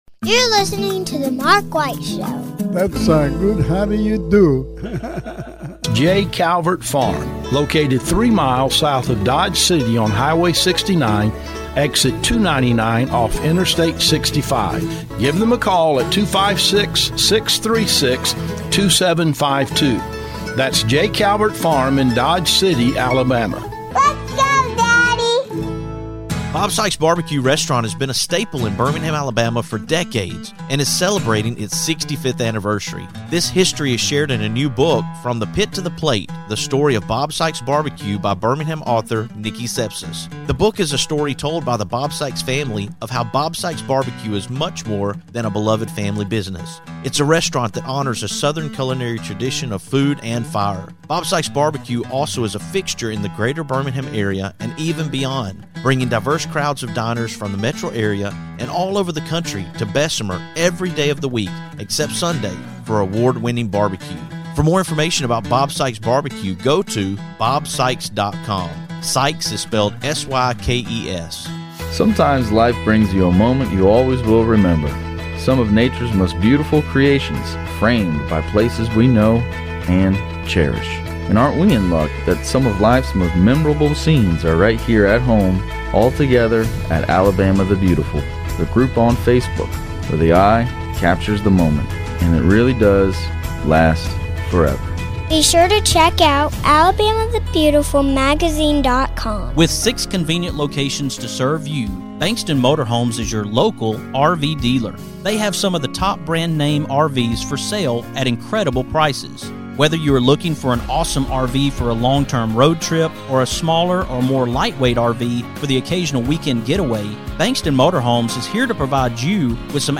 Forgive my stuffiness. I am a little under the weather.